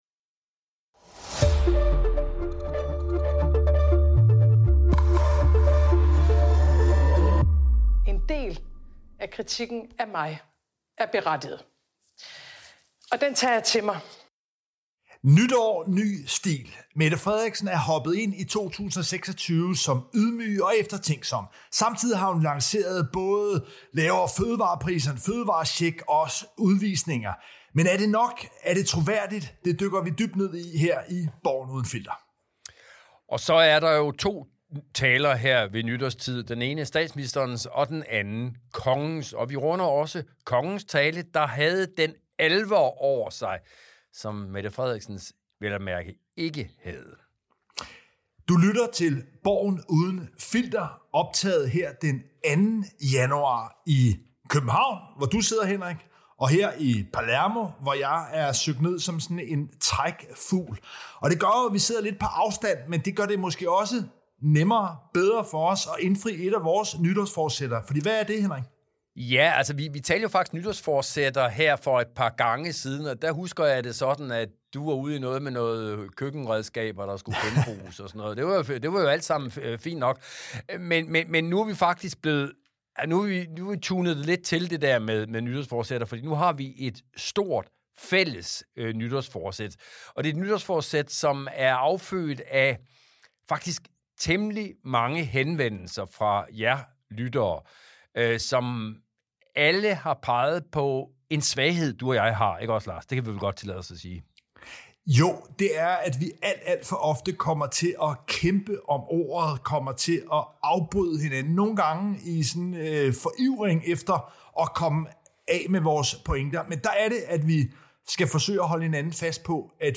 Hør også om, hvordan statsministeren snedigt udnyttede Kongen til at være budbringer af de knap så populære udmeldinger. De to politiske kommentatorer Lars Trier Mogensen og Henrik Qvortrup analyserer det dramatiske valgår i vente.
Værter: Henrik Qvortrup og Lars Trier Mogensen